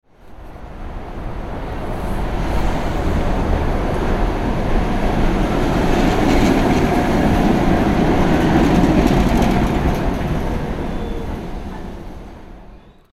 Realistic Tram Passing Close Sound Effect
Realistic-tram-passing-close-sound-effect.mp3